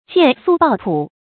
見素抱樸 注音： ㄒㄧㄢˋ ㄙㄨˋ ㄅㄠˋ ㄆㄧㄠˊ 讀音讀法： 意思解釋： 見「見素抱樸」。